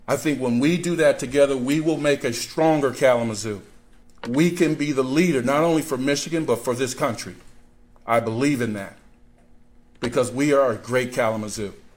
At a news conference Monday on the gunfight Sunday between a Battle Creek man, who was wanted on multiple charges, and Public Safety Officers, Chief Vernon Coakley said his department can’t do it alone, but it will take the whole community.